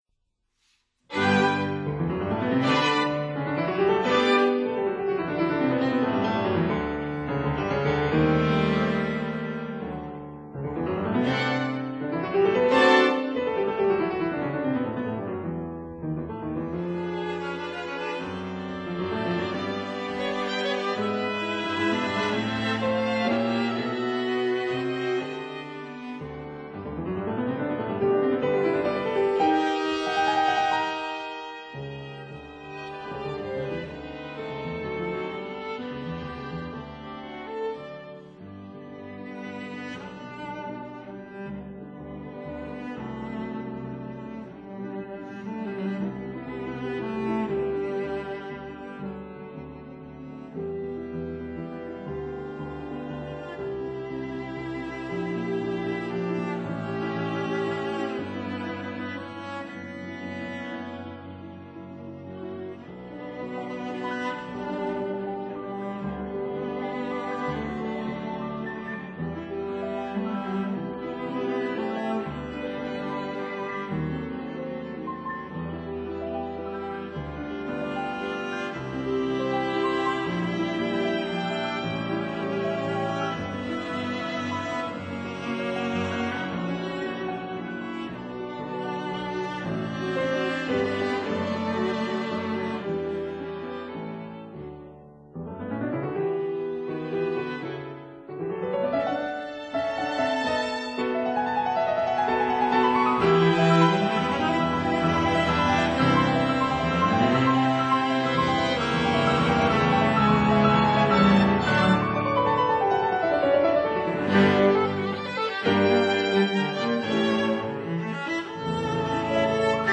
violin
viola
cello
piano Date